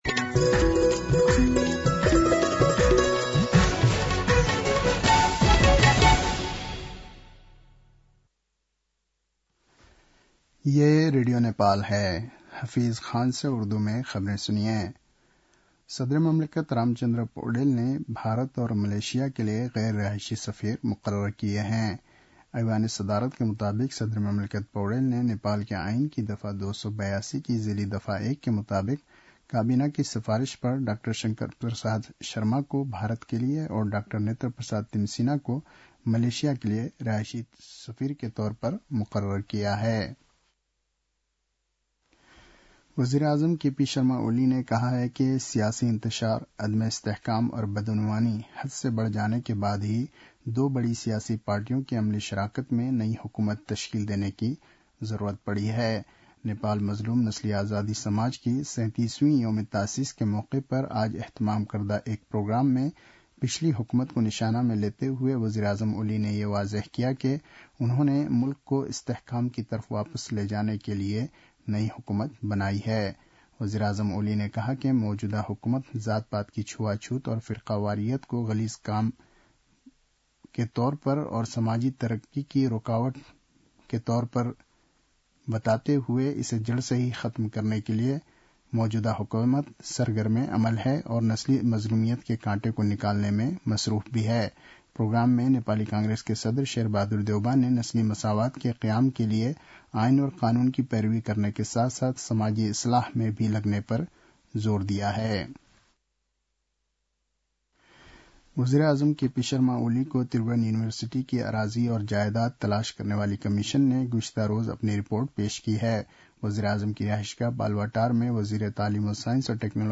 उर्दु भाषामा समाचार : ४ पुष , २०८१